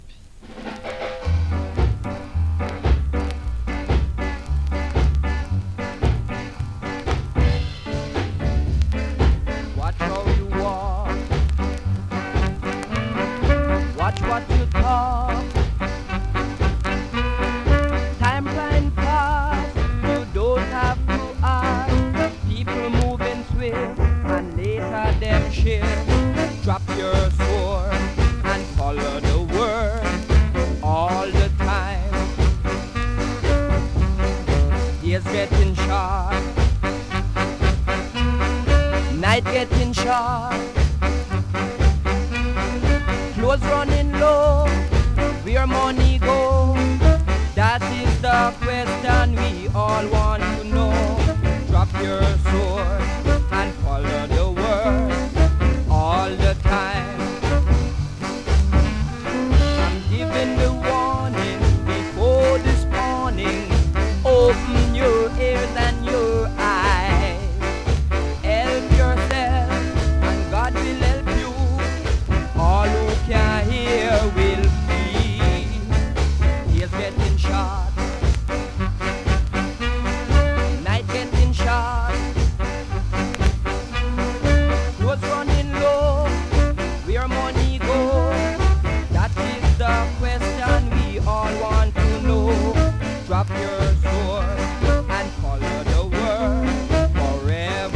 SKA!!
スリキズ、ノイズかなり少なめの